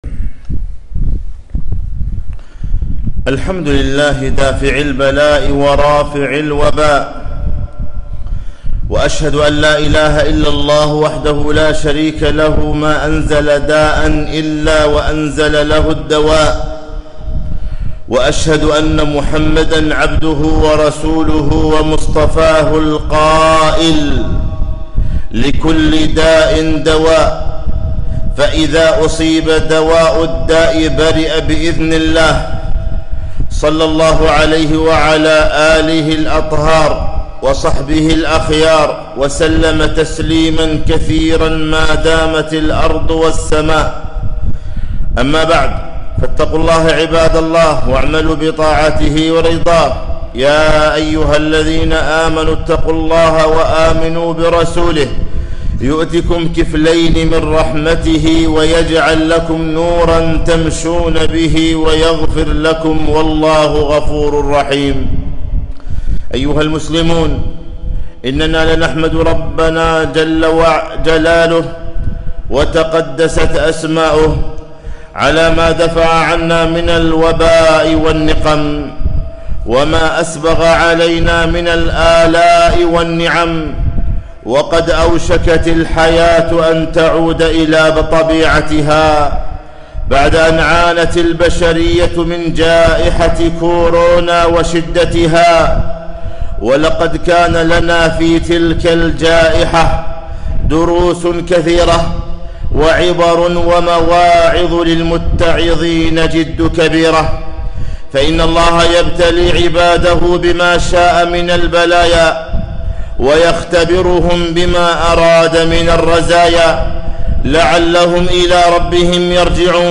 خطبة - الدروس والعبر المسفادة من جائحة كورونا